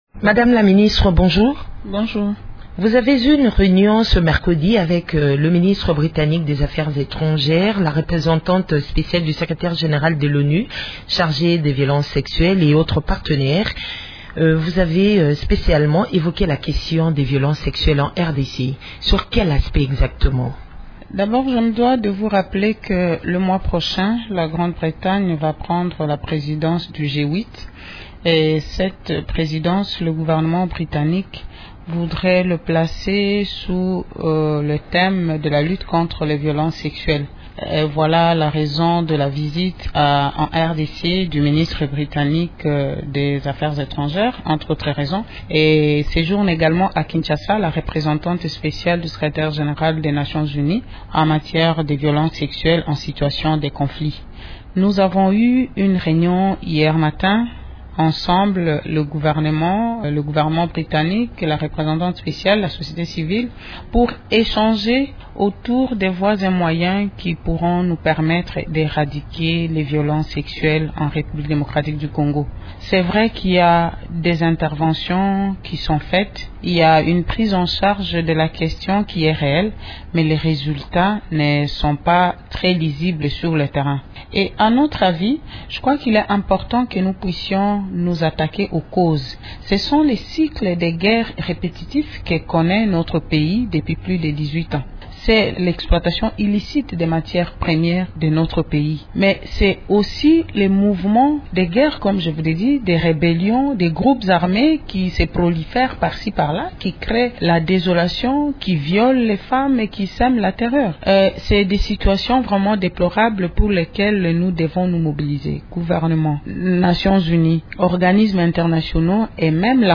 La ministre du Genre, Géneviève Inagosi, est l’invité de Radio Okapi ce jeudi.